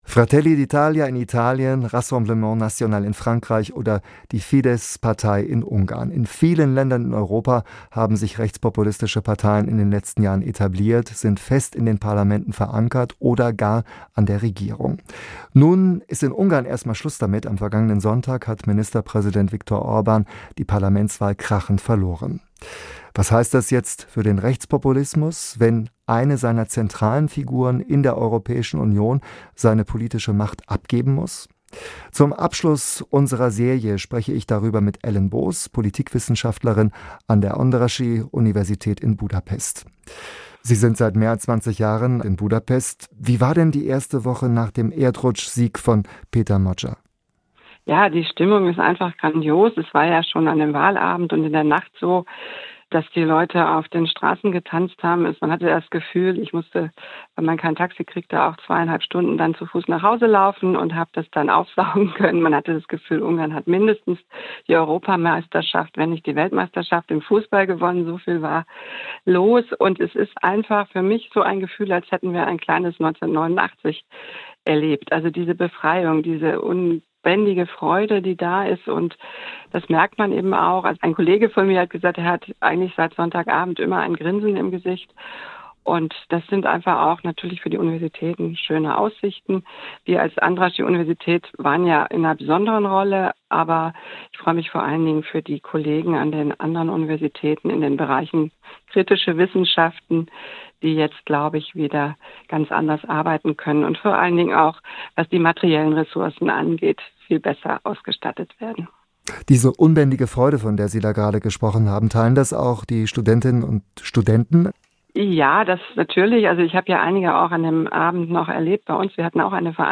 Die besten Interviews aus dem Radioprogramm SWR Aktuell: jederzeit zum Nachhören und als Podcast im Abo